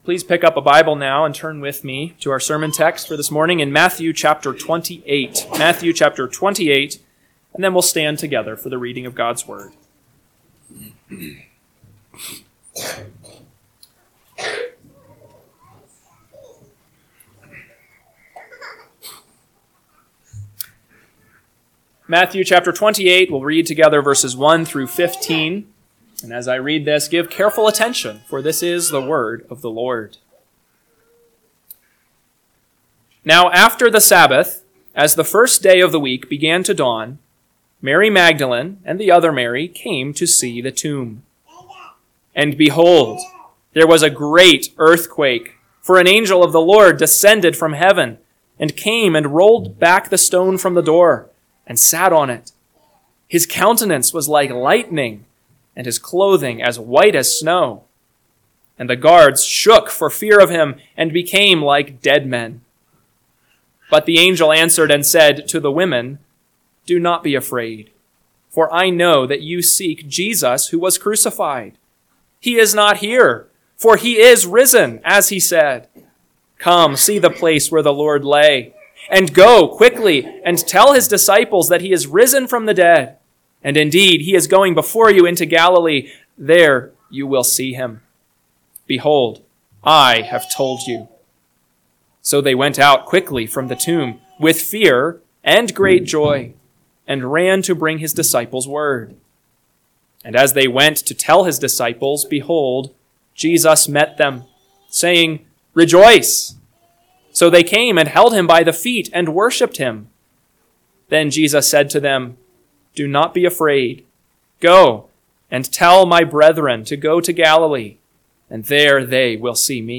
AM Sermon – 5/25/2025 – Matthew 28:1-15 – Northwoods Sermons